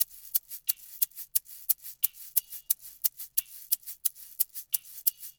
SMP SHAKER.wav